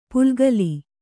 ♪ pulgali